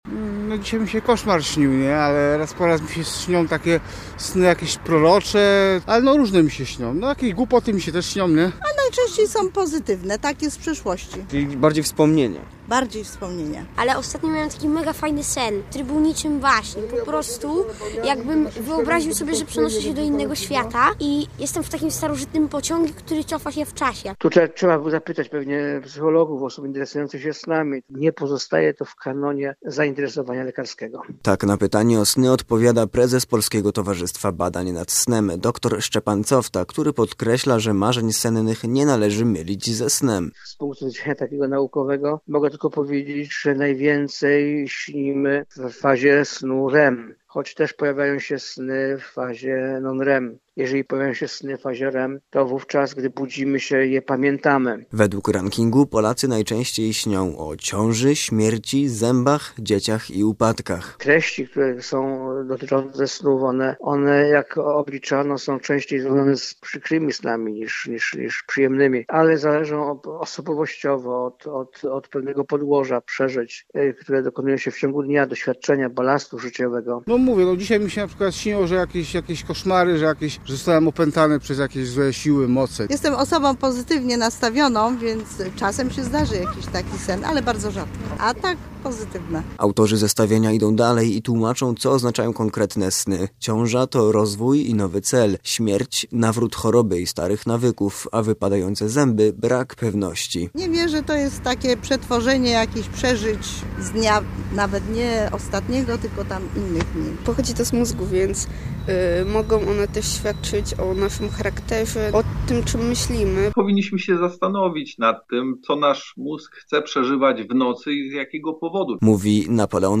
Poznaniacy, z którymi rozmawiał nasz reporter, sceptycznie podchodzą do znaczeń snów.